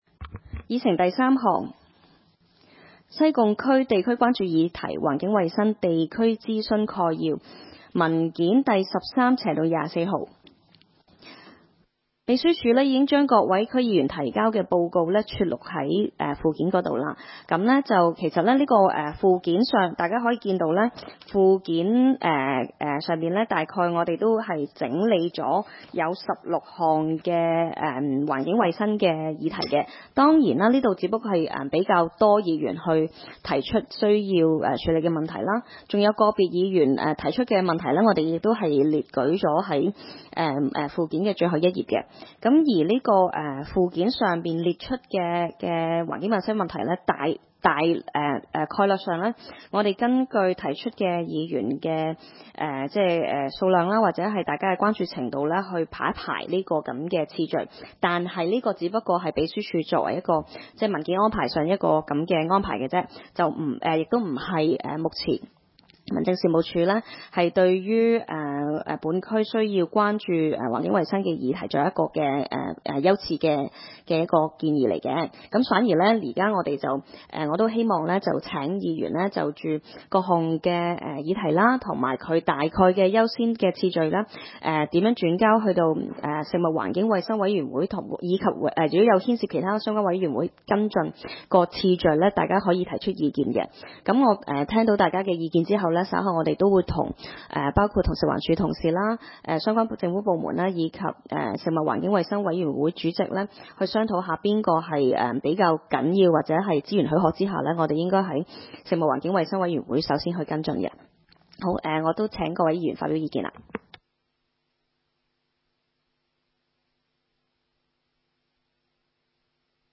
會議的錄音記錄